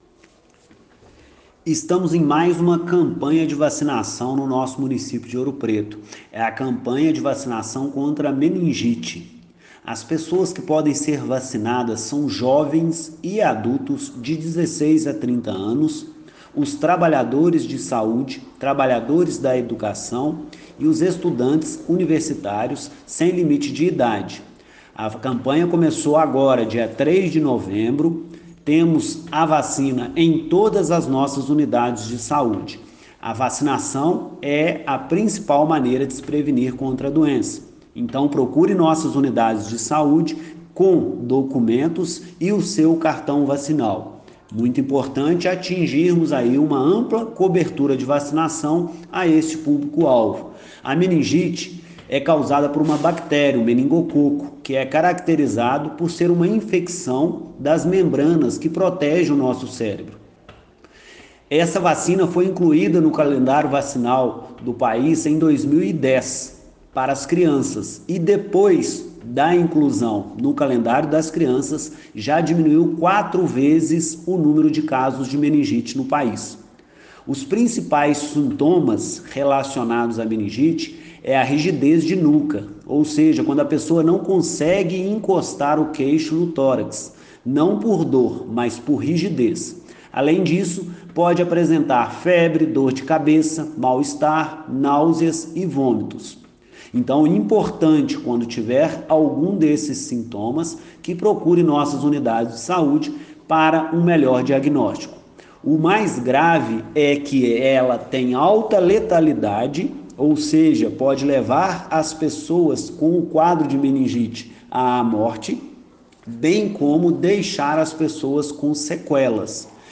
Secretário de Saúde de Ouro Preto Leandro Moreira convoca a população